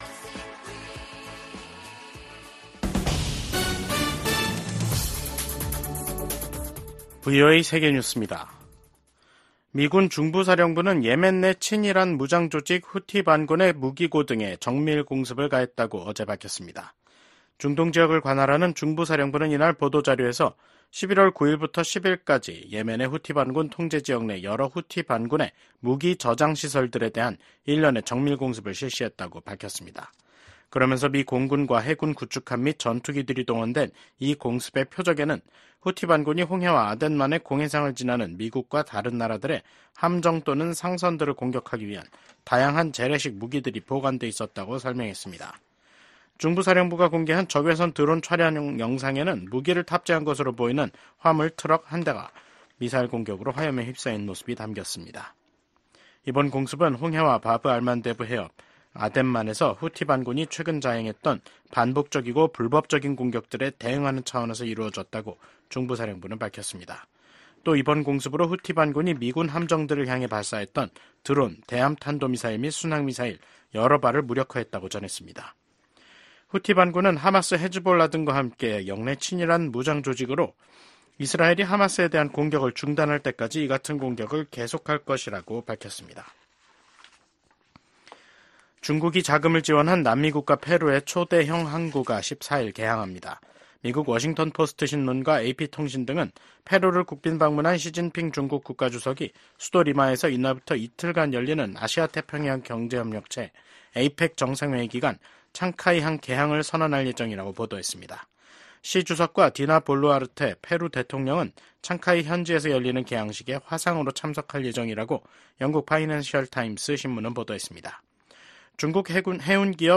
VOA 한국어 간판 뉴스 프로그램 '뉴스 투데이', 2024년 11월 13일 3부 방송입니다. 조 바이든 미국 대통령과 도널드 트럼프 대통령 당선인이 백악관에서 회동했습니다. 미국 백악관 국가안보보좌관은 북한군의 러시아 파병 문제가 차기 트럼프 행정부로 이어질 미국의 주요 안보 위협 중 하나라고 밝혔습니다.